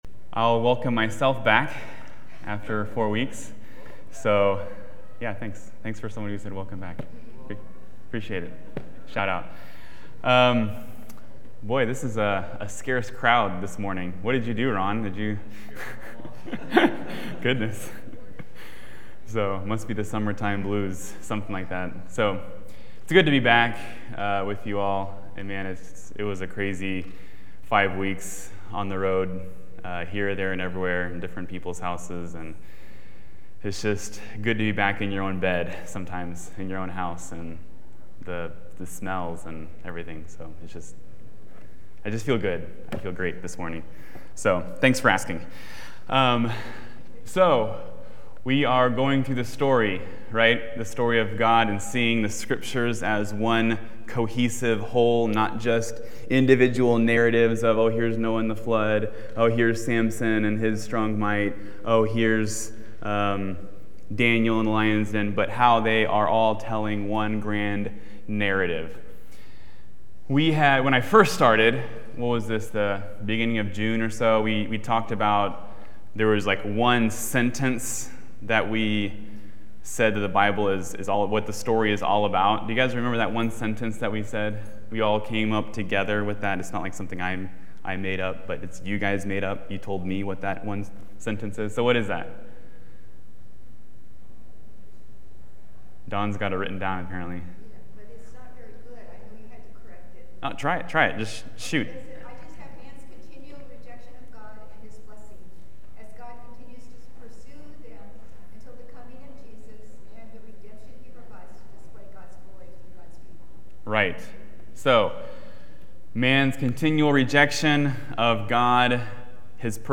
In this Adult Sunday School class